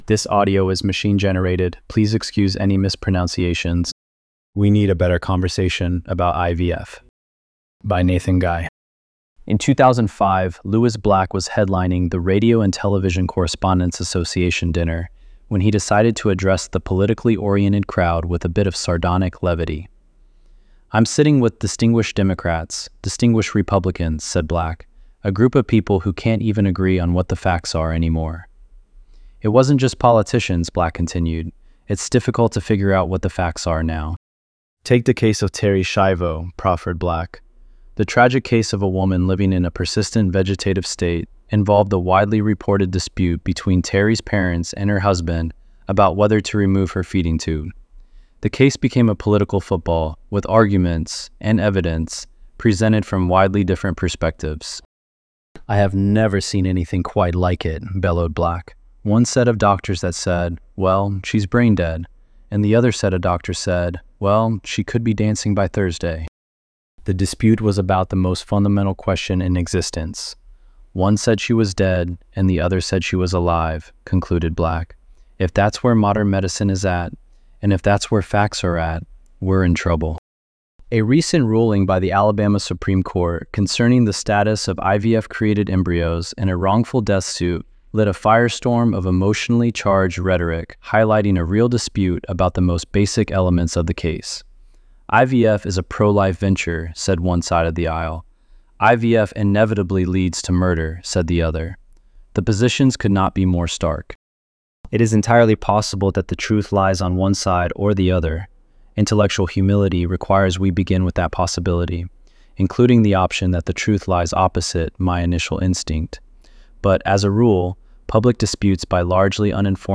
ElevenLabs_4_9.mp3